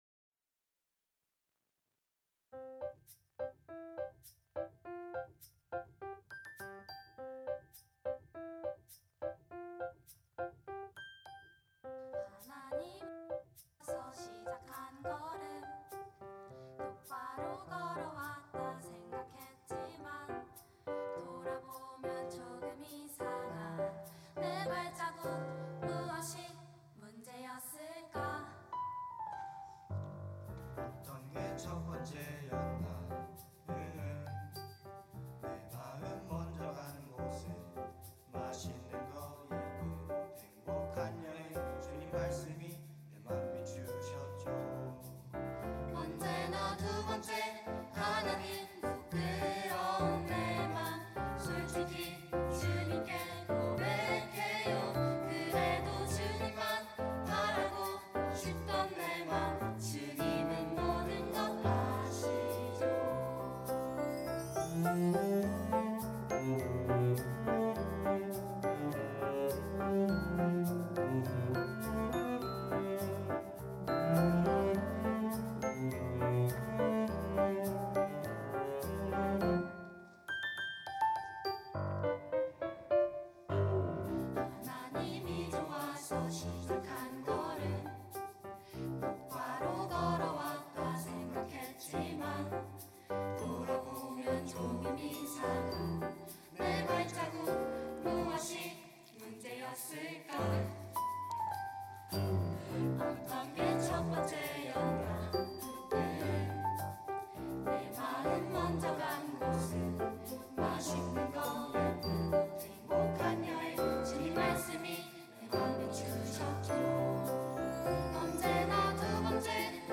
특송과 특주 - 최고로 사랑해요
청년부 33기